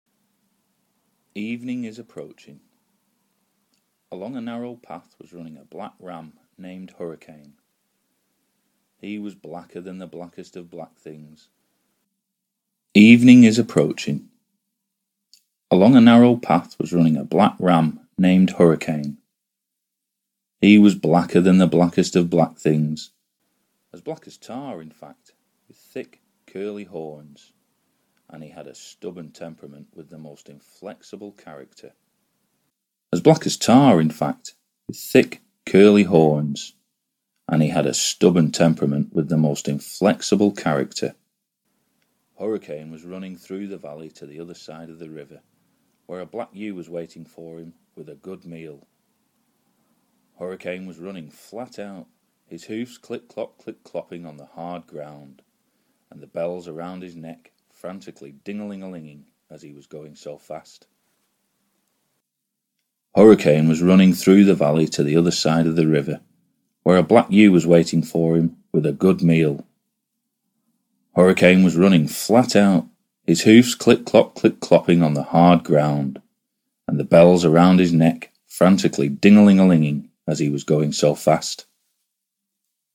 Чистка аудиозаписи от шумов Категория: Аудио/видео монтаж
Было задание подчистить MP3-файлы от шумов и увеличить их громкость.
Для удобства сравнения в файле чередуются короткие кусочки оригинала и редактированной записи.